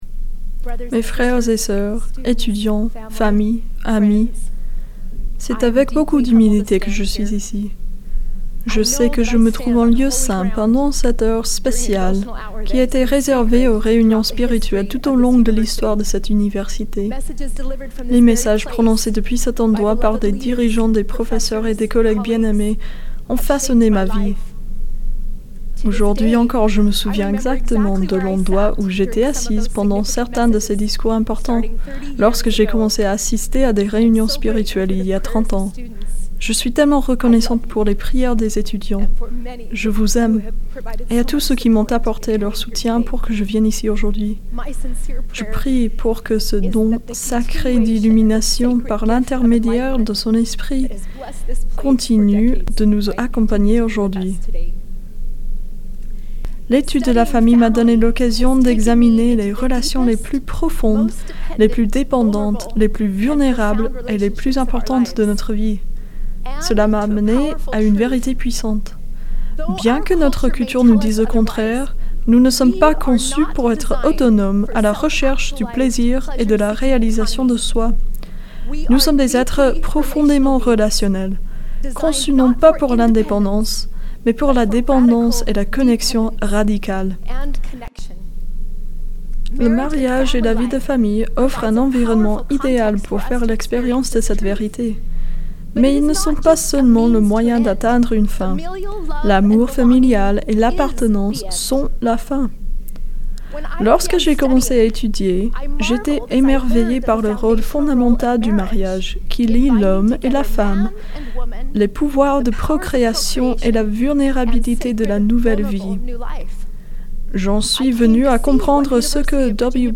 Réunion spirituelle